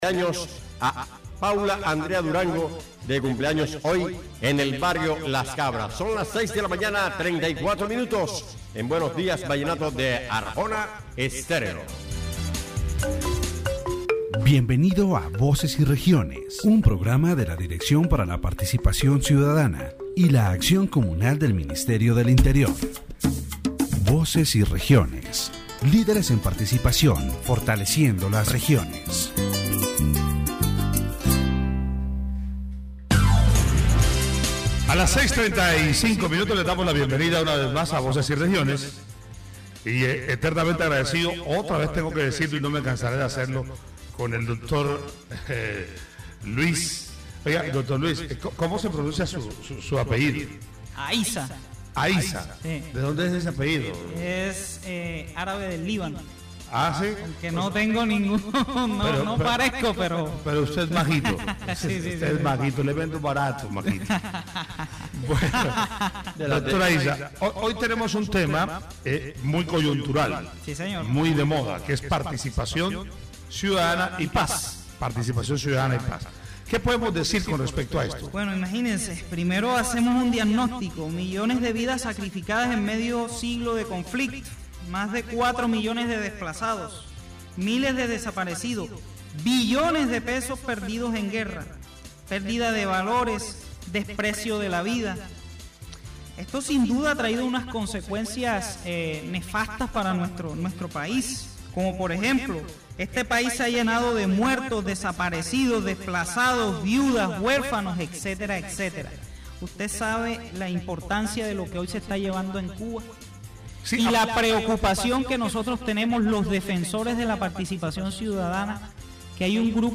The radio program "Voices and Regions" discussed the role of citizen participation in Colombia's peace process.